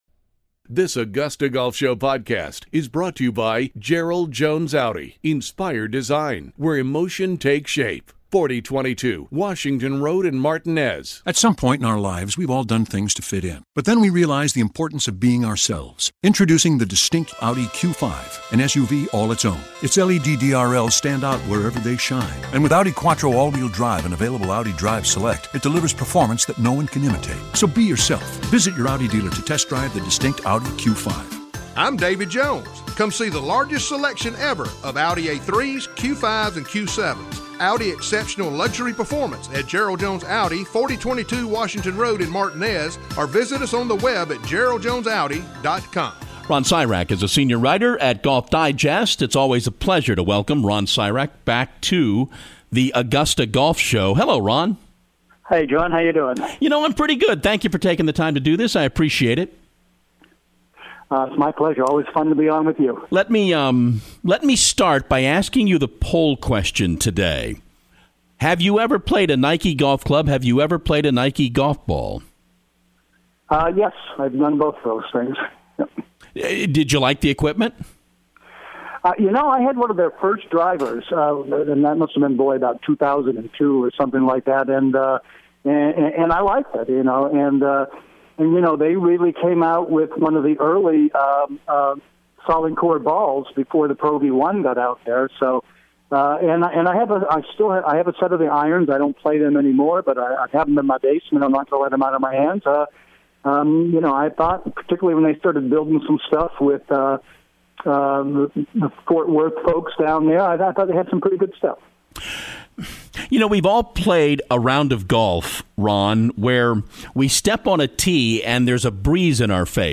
The Augusta Golf Show Interview